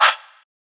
Murda (Vox).wav